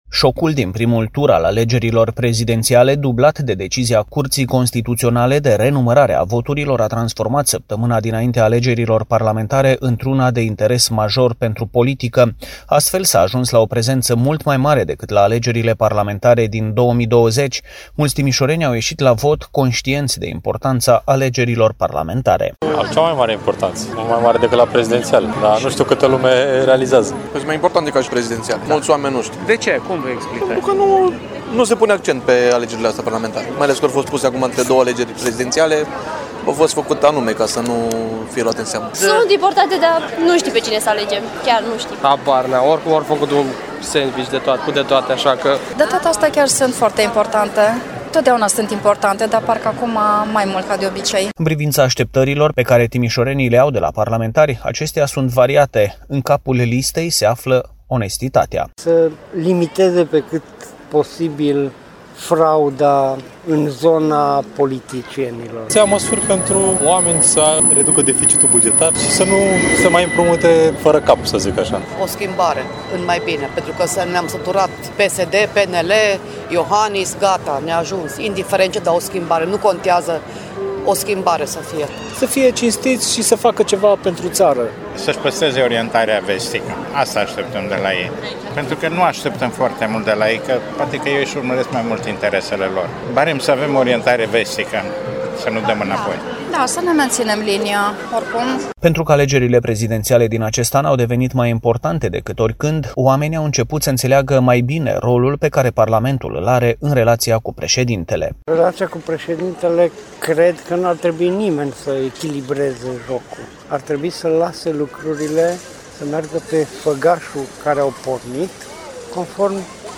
Reportaj | Așteptările timișorenilor de la viitorii parlamentari - Radio România Timișoara